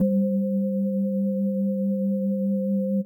Rhodes - LAX.wav